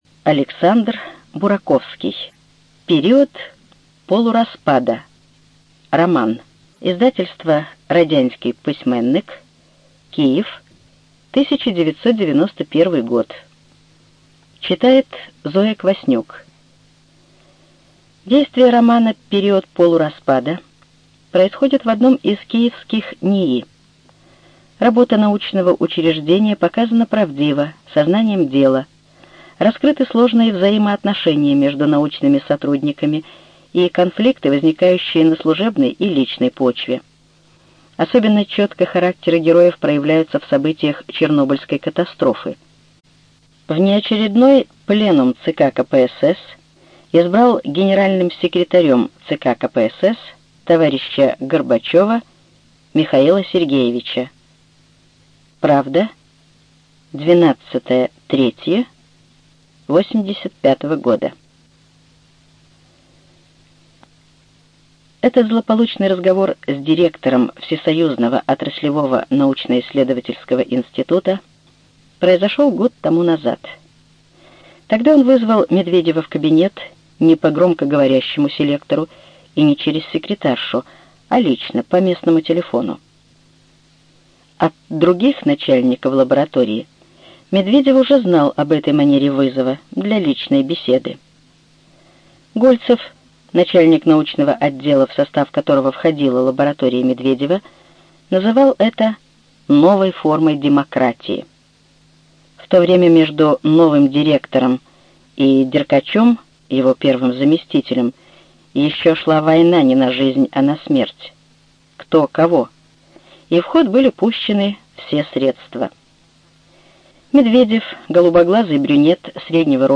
ЖанрСоветская проза
Студия звукозаписиРеспубликанский дом звукозаписи и печати УТОС